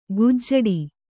♪ gujjaḍi